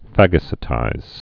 (făgə-sĭ-tīz, -sī-)